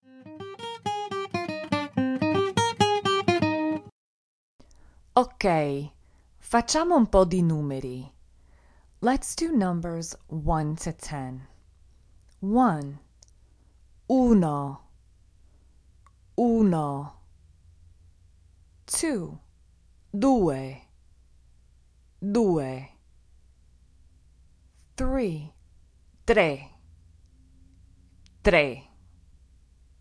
Easy Italian Audio Course for Beginners, Vol 1 - 04 Numbers